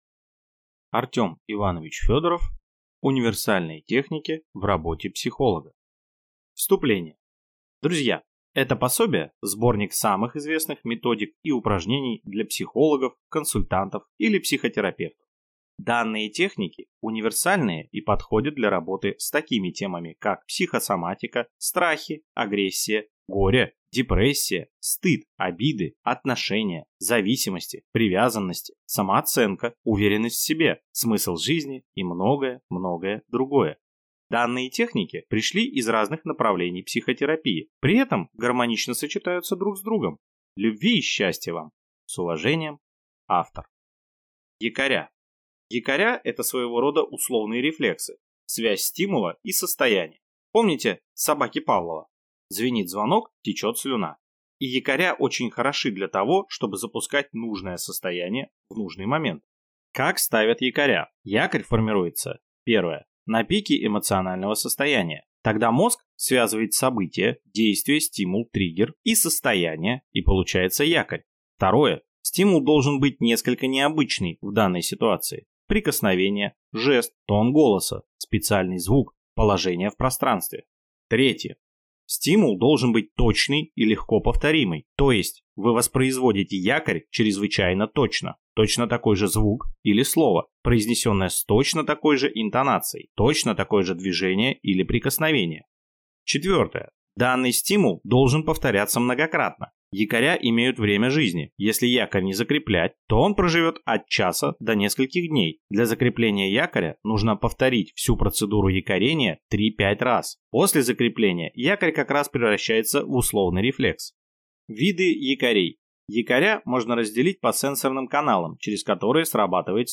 Аудиокнига Универсальные техники в работе психолога | Библиотека аудиокниг